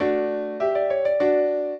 piano
minuet0-6.wav